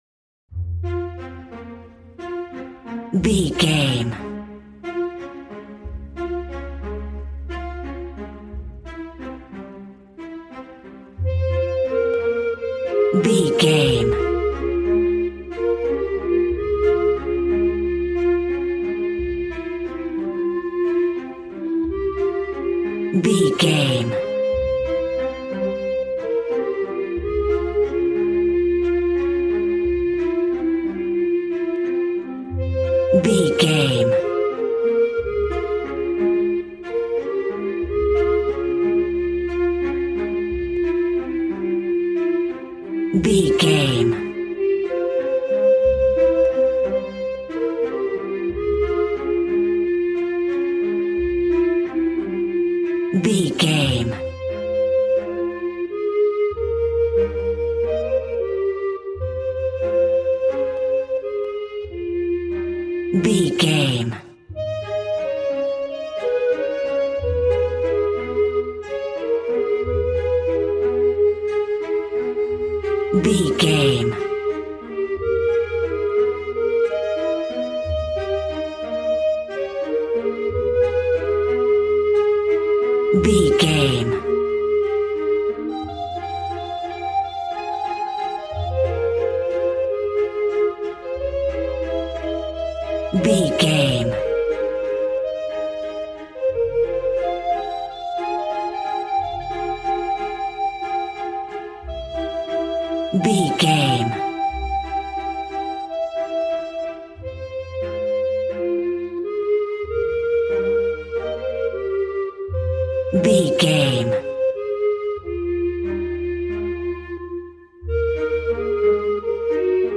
A warm and stunning piece of playful classical music.
Regal and romantic, a classy piece of classical music.
Aeolian/Minor
regal
piano
violin
strings